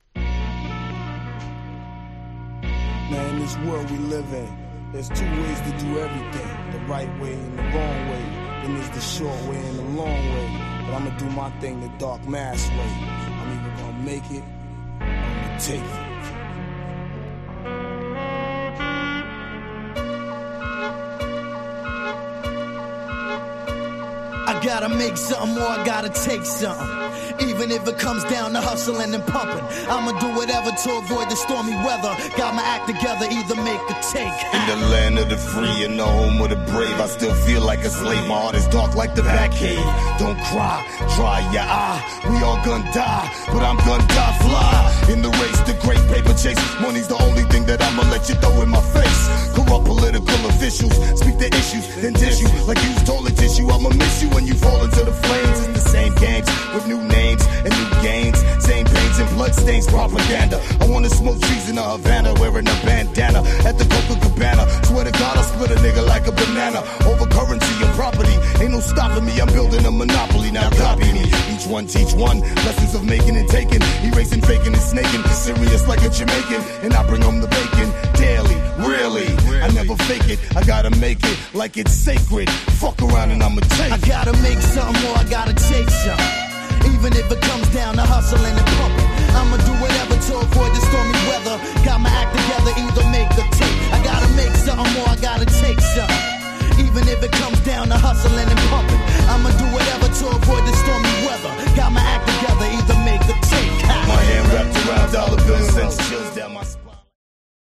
Hit 90's US Hip Hop !!
ナイン Boon Bap ブーンバップ